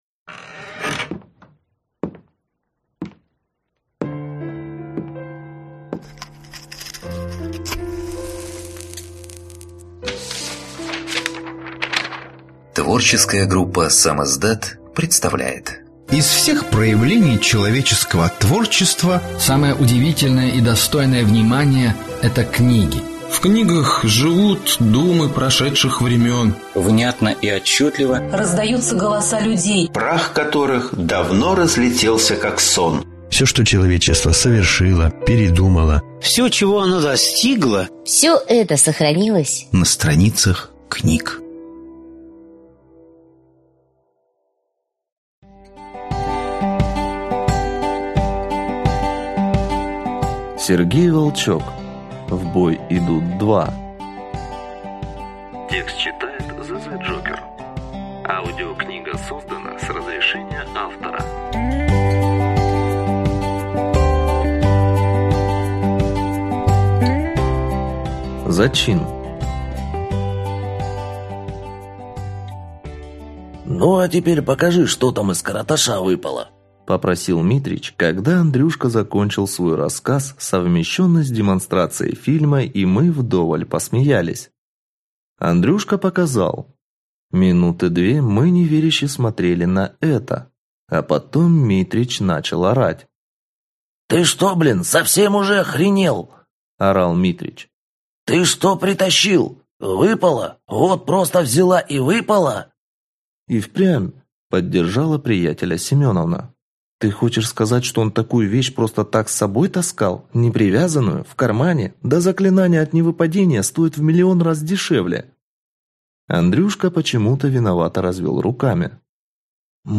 Аудиокнига В бой идут… Книга вторая | Библиотека аудиокниг